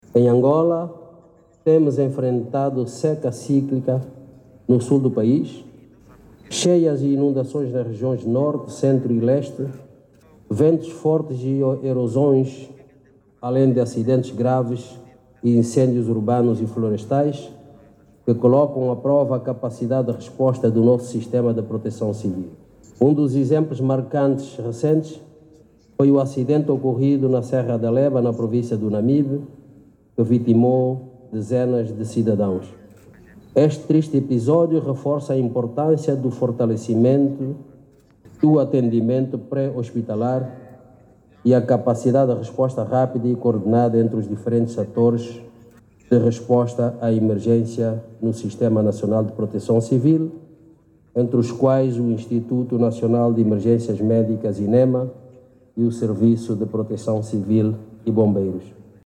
Ao intervir na abertura da conferência nacional intitulada “Integração de Estratégias para a Redução do Risco e Resposta a Emergências”, organizada pelo Ministério do Interior, o governante sublinhou que o país tem vindo a enfrentar vários fenómenos naturais resultantes do aquecimento global.